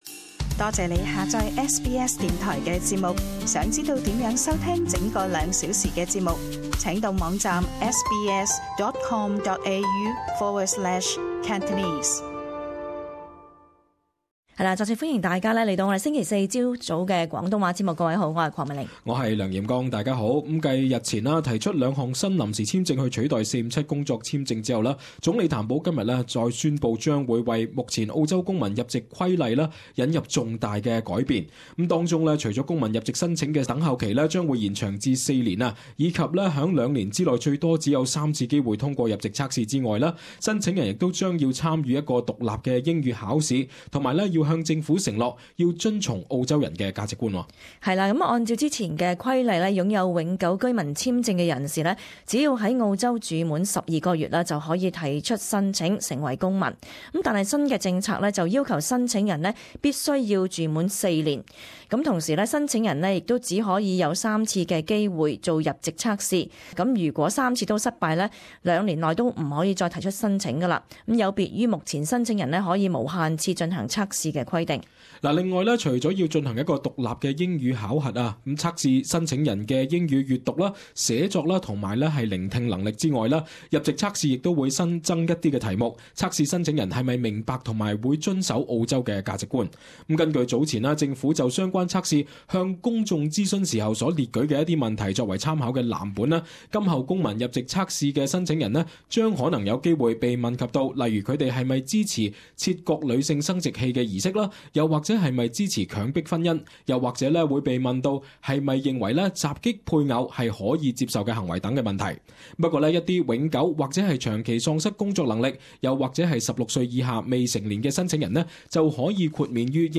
【時事報導】譚保宣布修改公民入藉規例